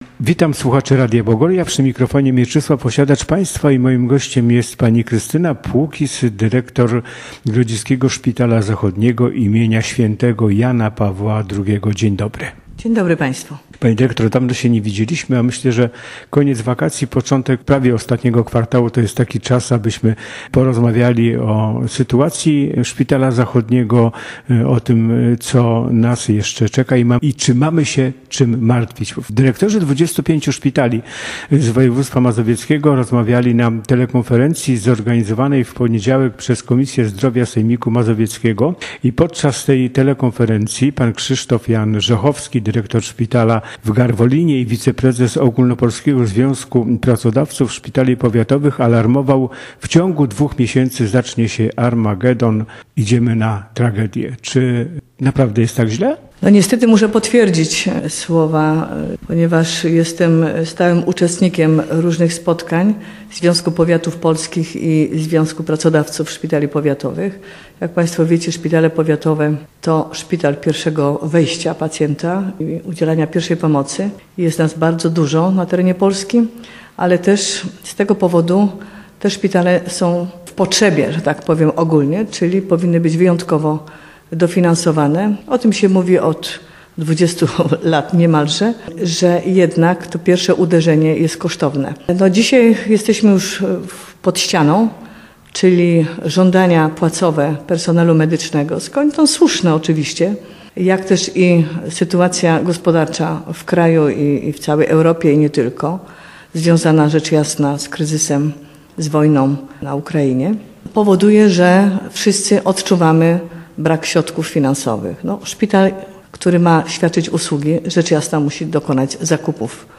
Wywiad